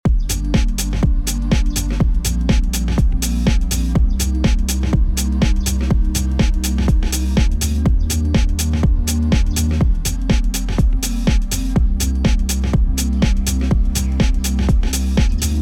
sustained-chords-example.mp3